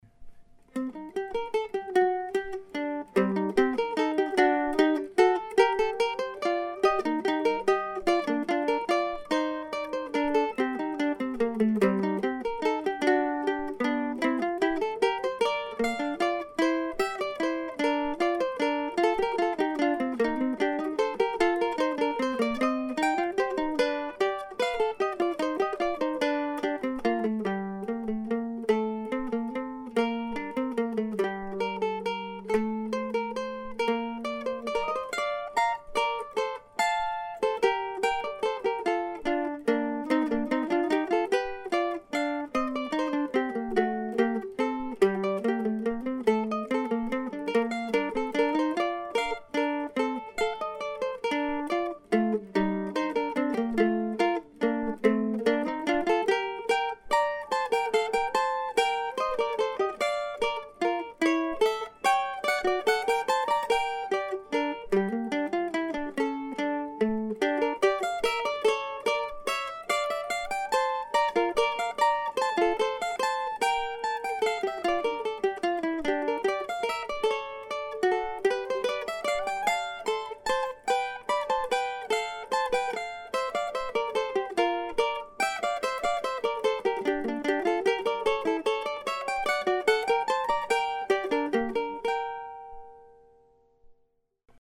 This time I'm offering another duo from my Midwestern Mandolin Duos collection, one which defied all my attempts to think of a colorful title.